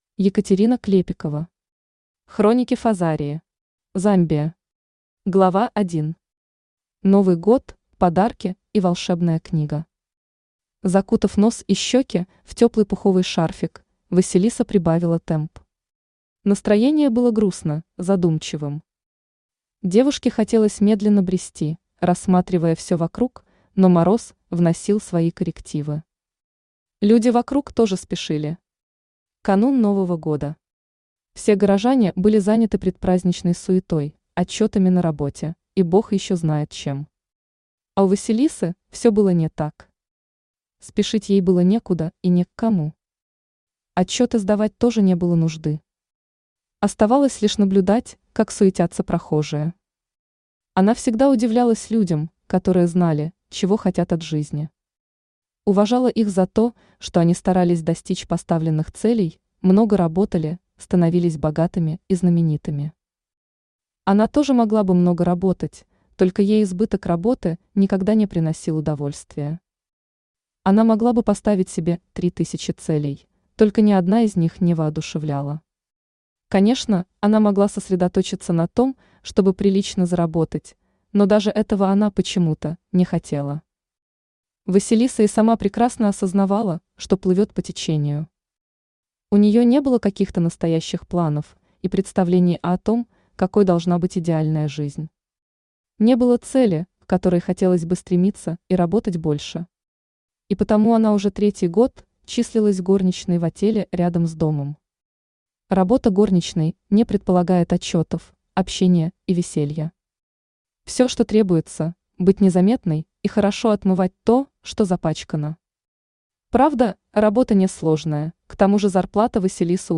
Аудиокнига Хроники Фозарии. Замбия | Библиотека аудиокниг
Замбия Автор Екатерина Клепикова Читает аудиокнигу Авточтец ЛитРес.